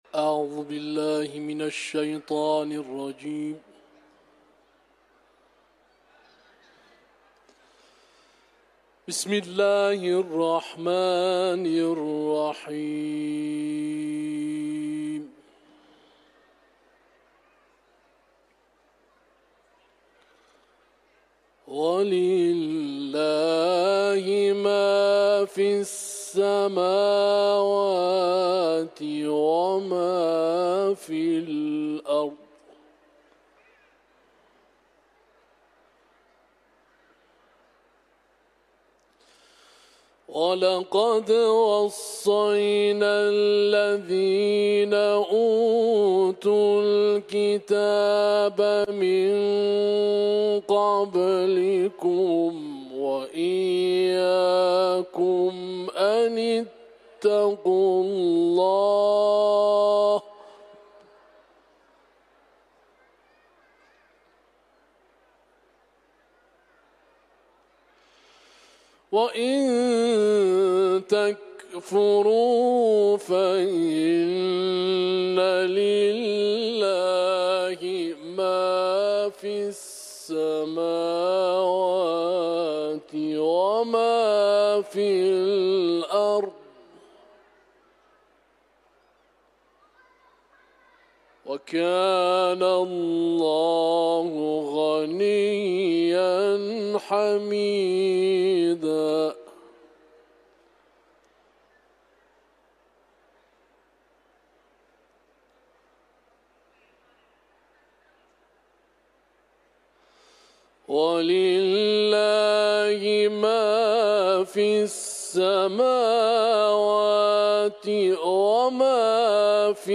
تلاوت قرآن ، سوره نساء